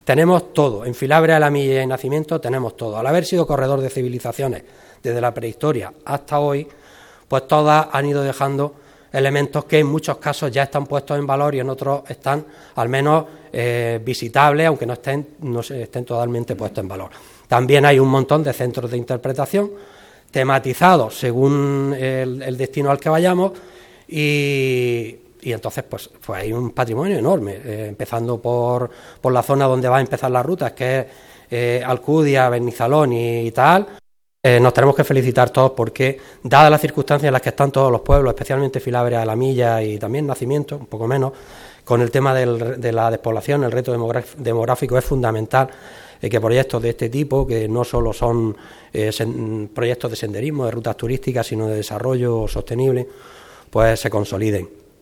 El Patio del Mandarino de la Diputación de Almería ha sido el escenario para la puesta de largo de la XII edición de las Rutas del Almendro en Flor Filabres-Alhamilla que, por primera vez, incluye un recorrido senderista en la comarca del Alto Nacimiento y suma también la ruta de Turrillas. Este año, crecen las rutas hasta completar una docena y la primera tendrá lugar en Benizalón el 25 de enero. Estas Rutas, vuelven a estar aparejadas a las Jornadas Gastronómicas de la Almendra, que este año cumplen su X edición.